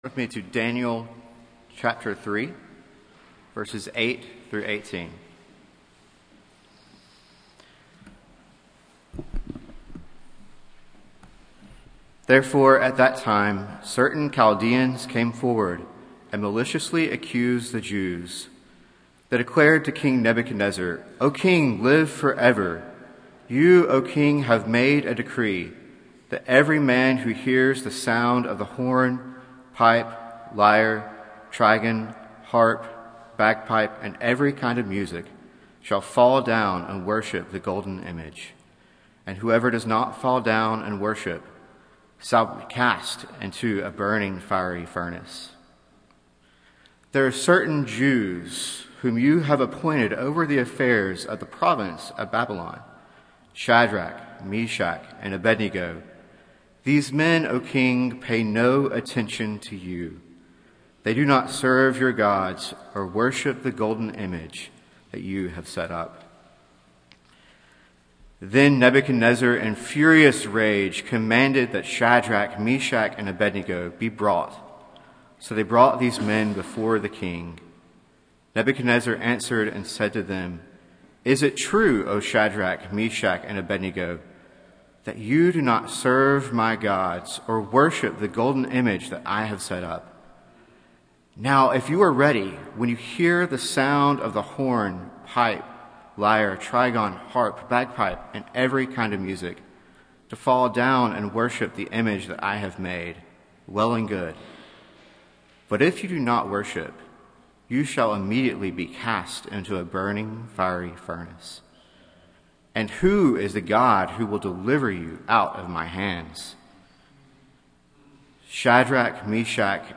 Passage: Daniel 3:1-20 Service Type: Sunday Morning